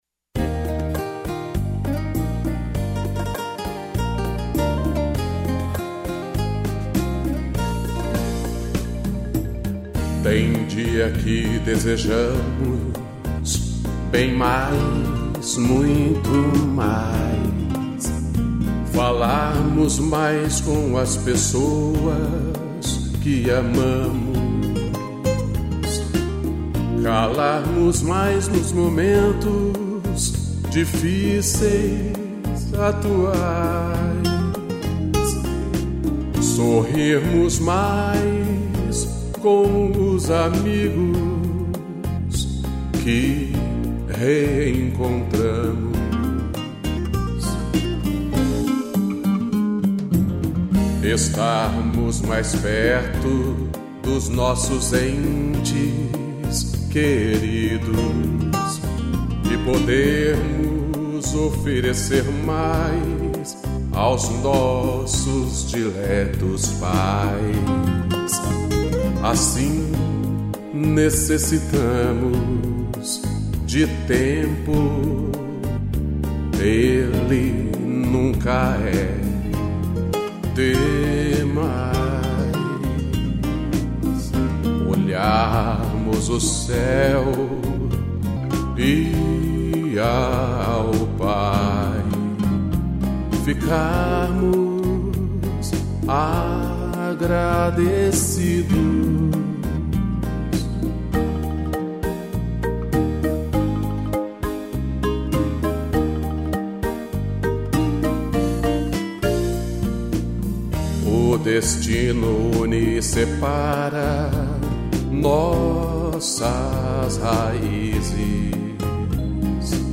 interpretação e violão